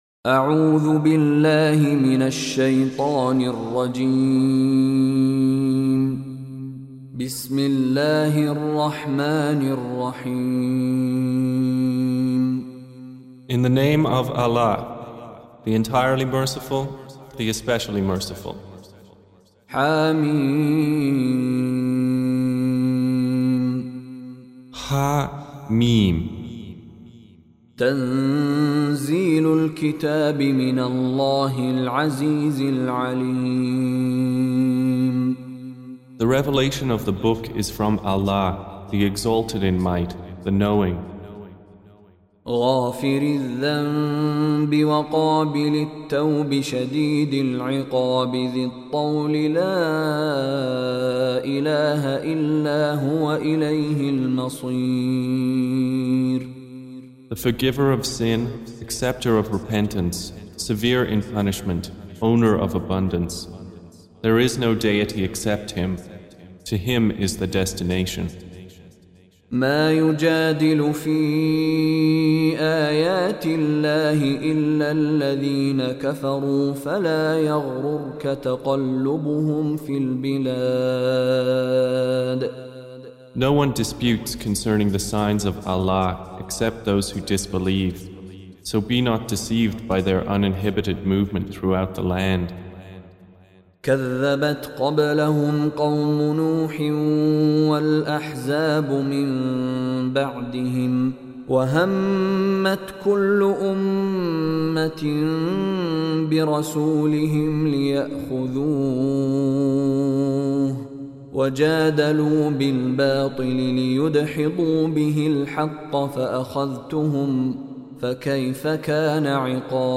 Audio Quran Tarjuman Translation Recitation
Surah Repeating تكرار السورة Download Surah حمّل السورة Reciting Mutarjamah Translation Audio for 40. Surah Gh�fir سورة غافر N.B *Surah Includes Al-Basmalah Reciters Sequents تتابع التلاوات Reciters Repeats تكرار التلاوات